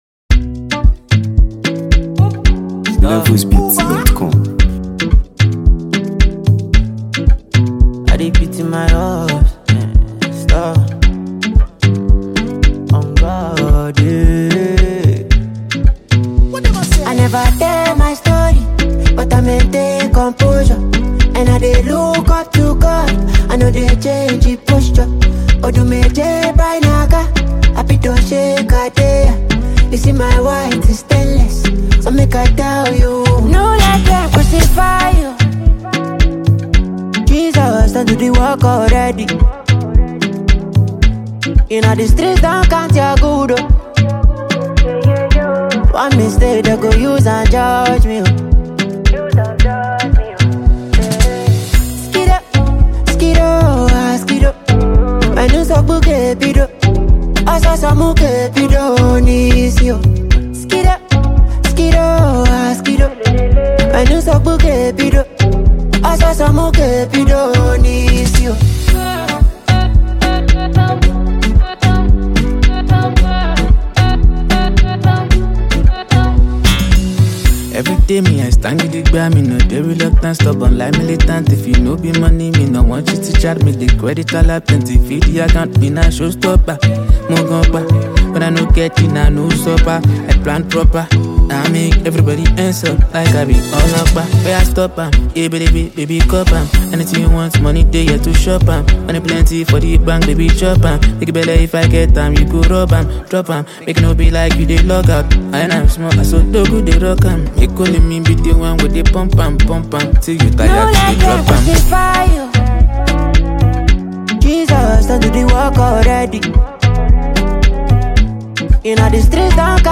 packed with infectious melodies